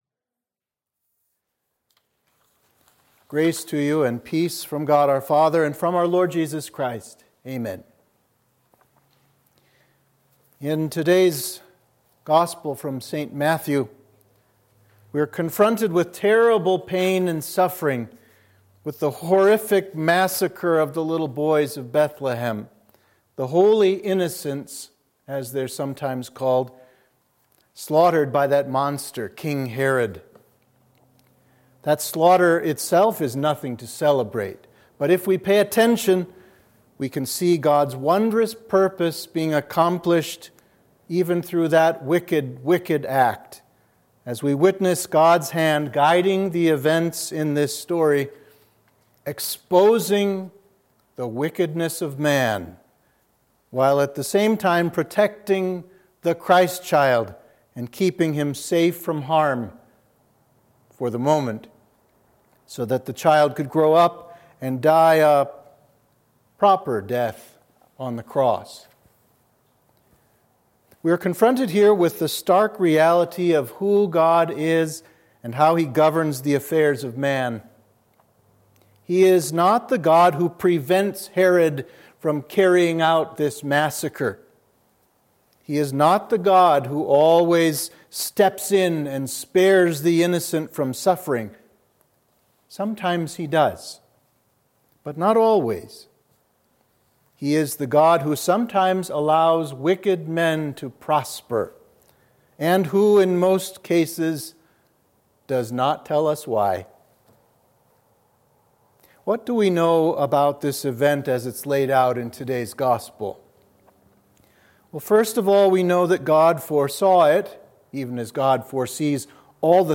Sermon for Holy Innocents’ Day